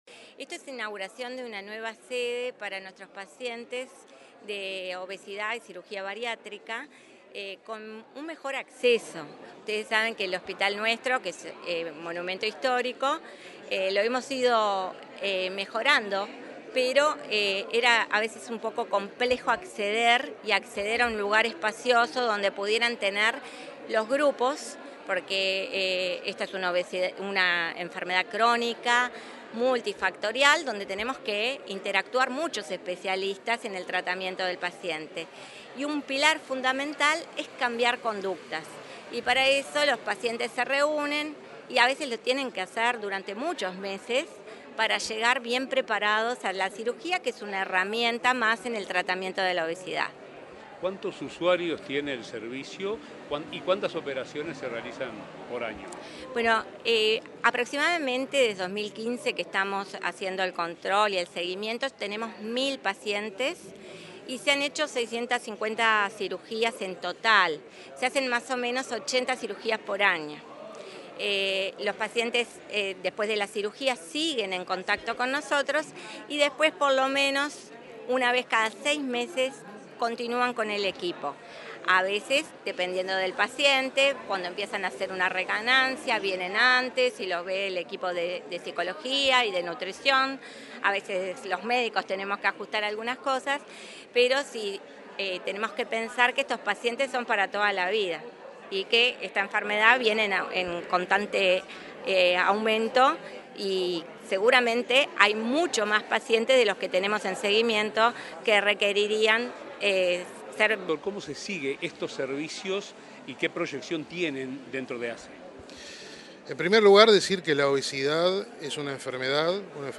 Declaraciones de autoridades de ASSE en el hospital Maciel